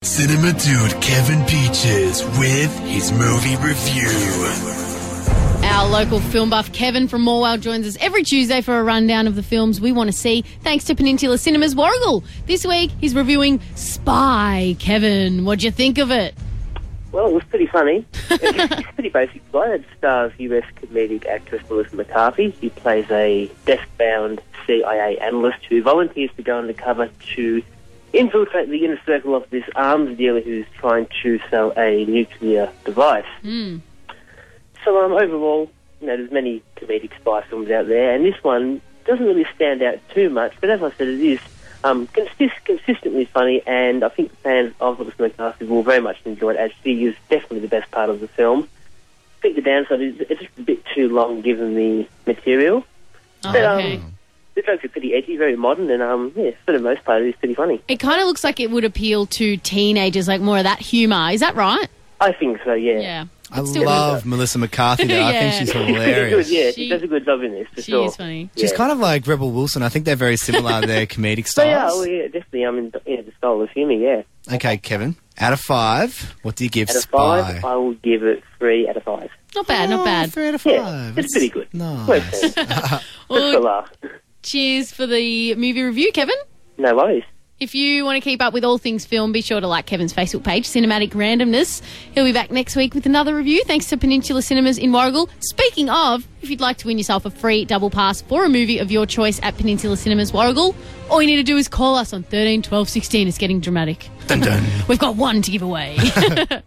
Review: Spy (2015)